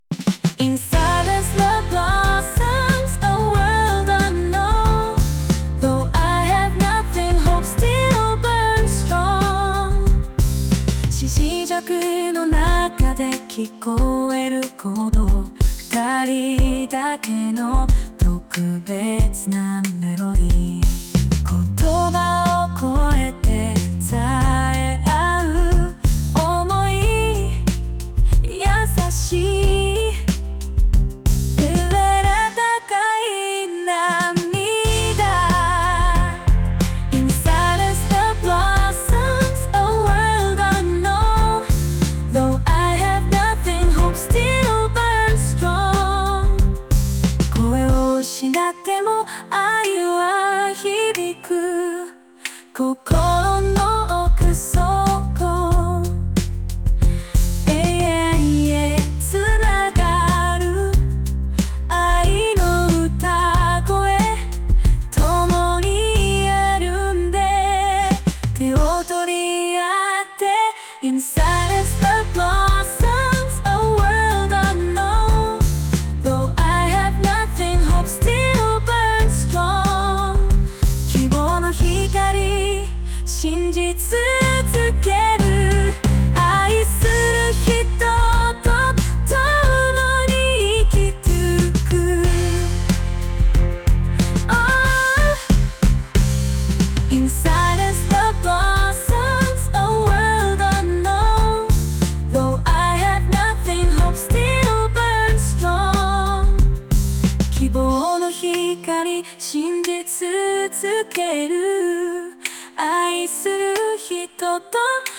ＡＩ（Suno）の力を借りてイメージして作りました。
時代劇とイメージは違うけど曲は良いモノが出来たと思う。